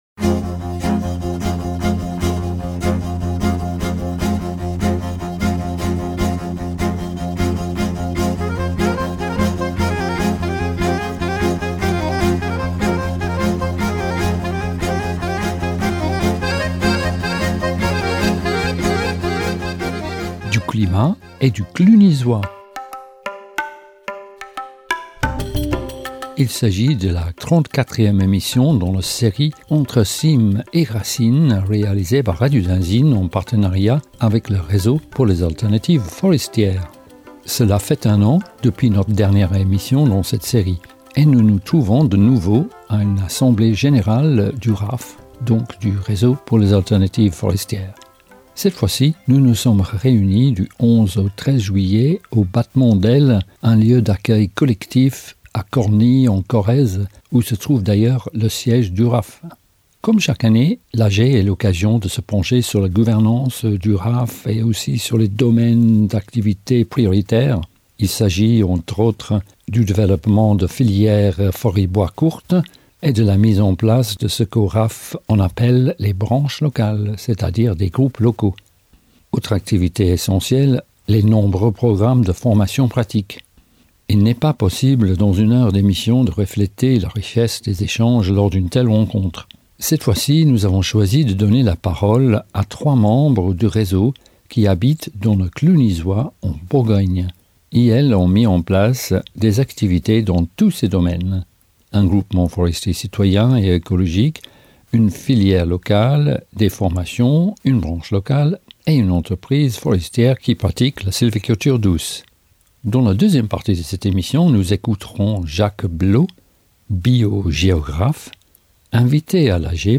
Les entretiens et les enregistrements qui constituent la base de cette série d’émissions ont été réalisées lors des rencontres et des ateliers organisés par le Réseau.